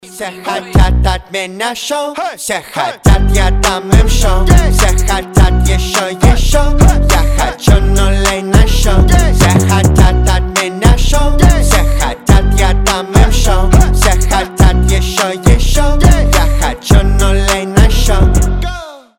• Качество: 320, Stereo
качающие